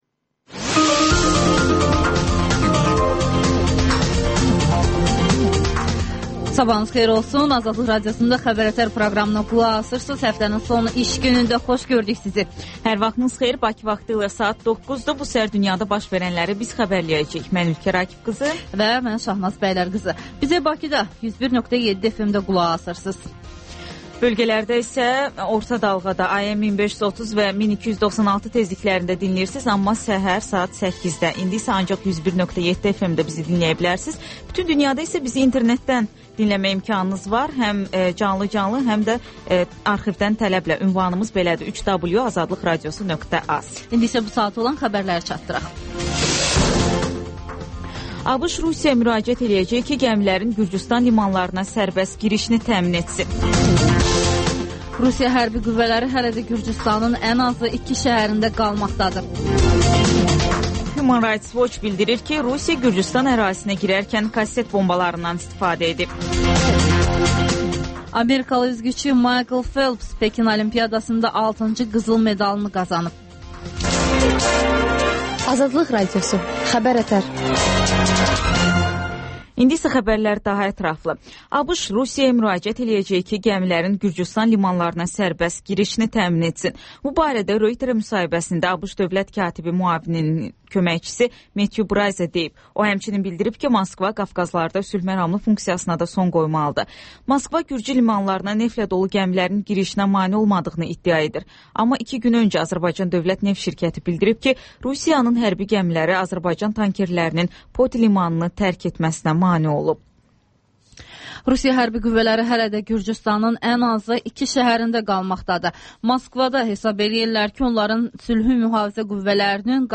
Xəbər-ətər: xəbərlər, müsahibələr və 14-24: Gənclər üçün xüsusi veriliş